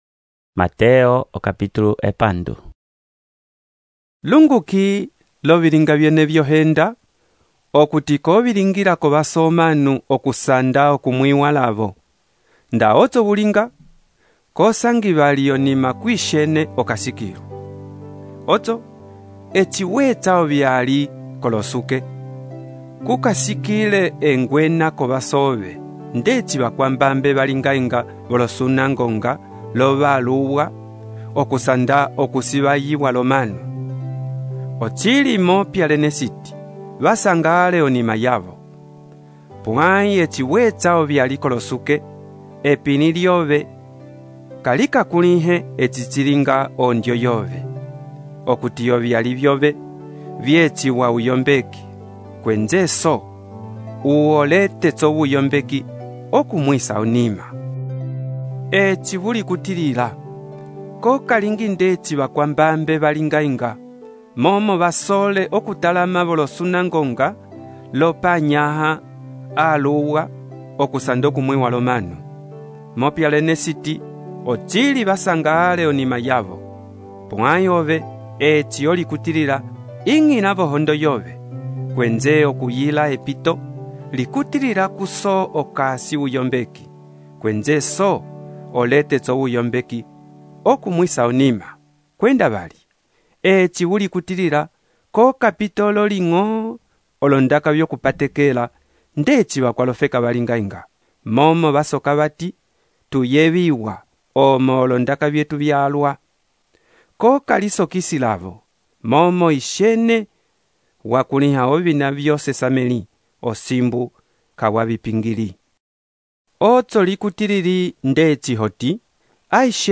texto e narração , Mateus, capítulo 6